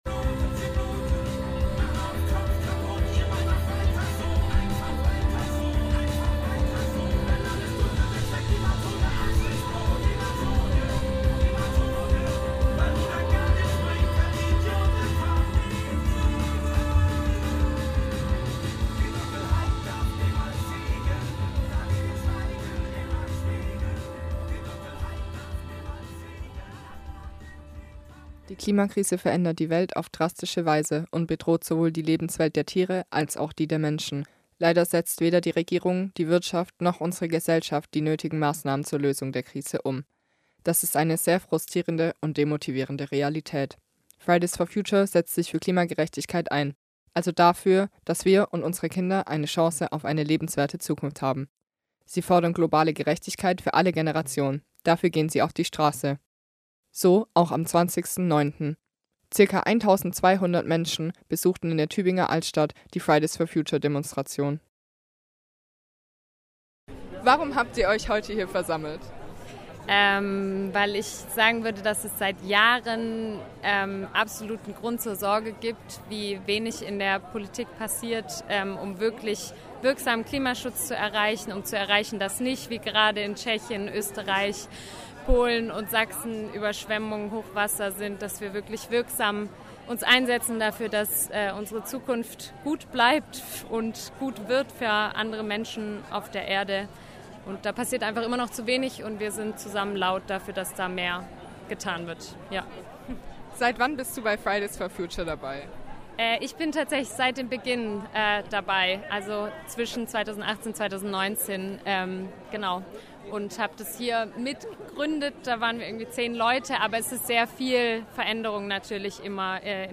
Zum Klimastreik letzten Freitag in Tübingen kamen geschätzt 1.200 Menschen. Hier hört Ihr Interviews mit Redner*innen, mit Streikenden und die Abschlusskundgebung von Fridays for Future.